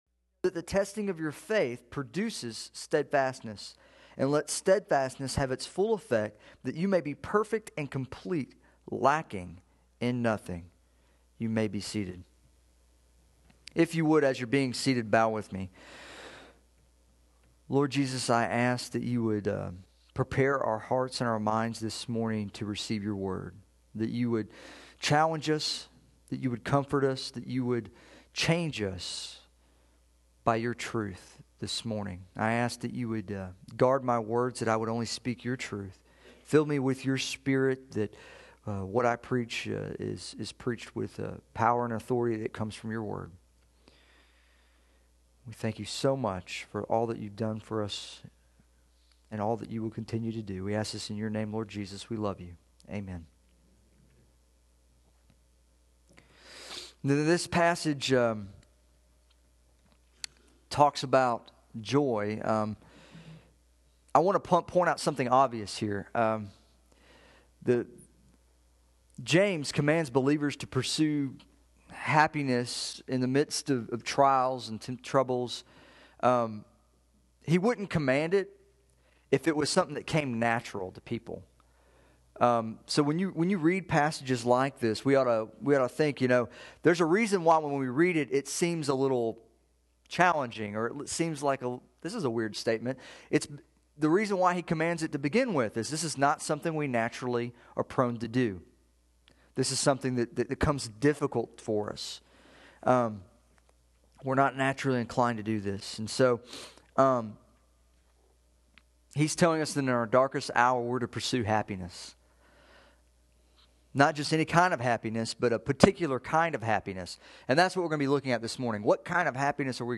Sermon Audio
Listen to our Sunday morning service!